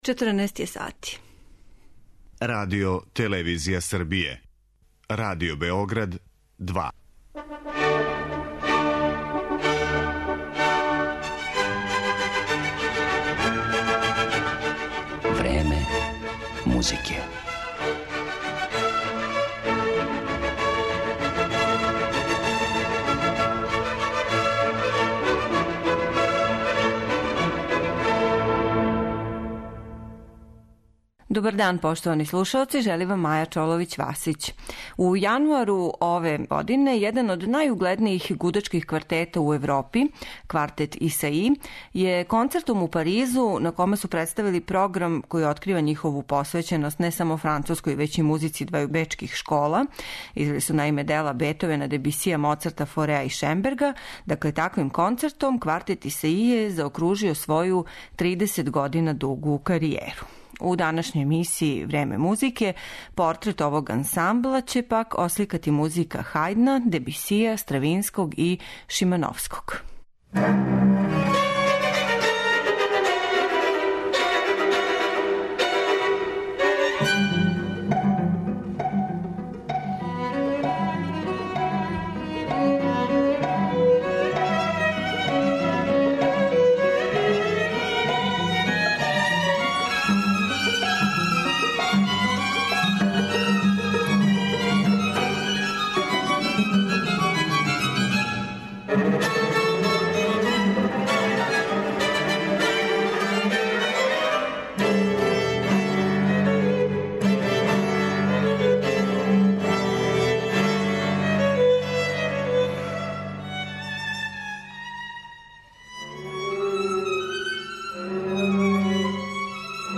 француски гудачки квартет